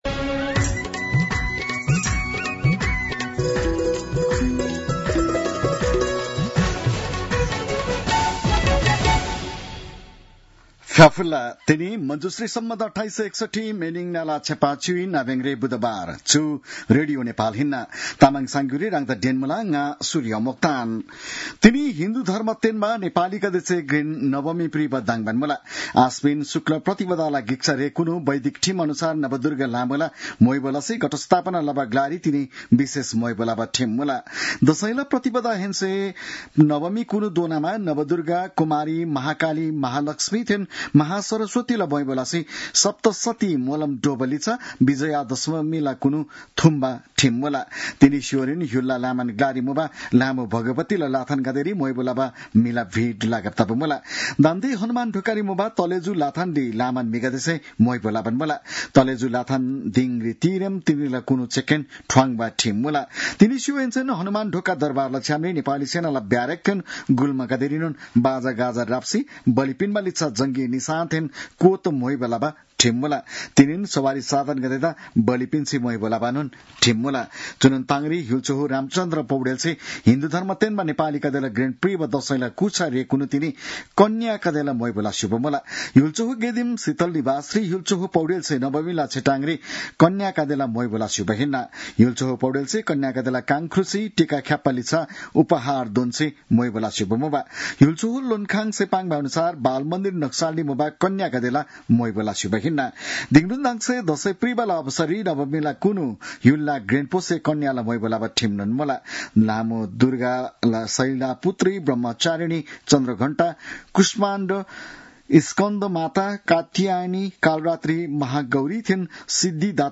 तामाङ भाषाको समाचार : १५ असोज , २०८२